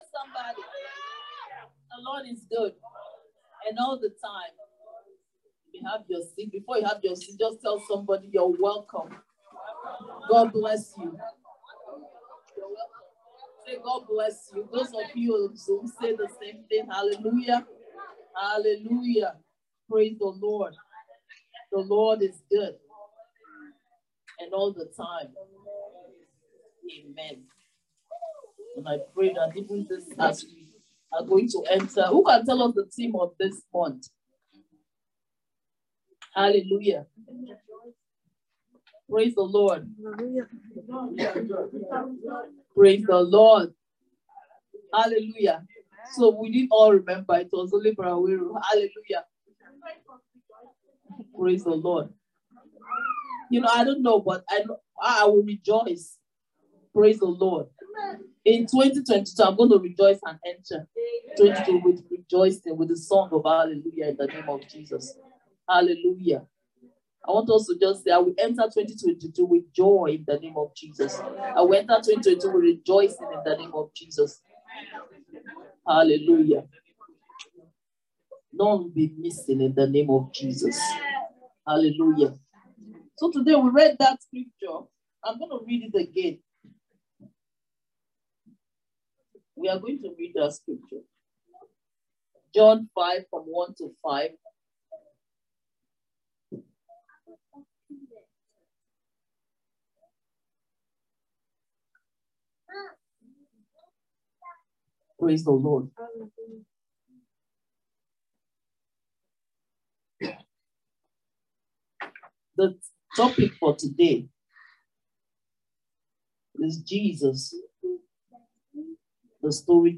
This was the sermon from the last Sunday in December.
1226-Sunday-Service.mp3